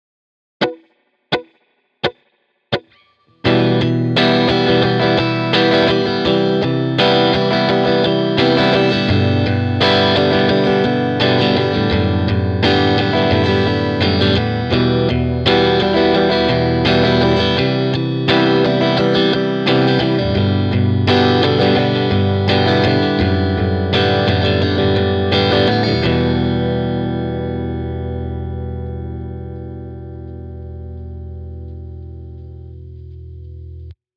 On commence sans les liaisons :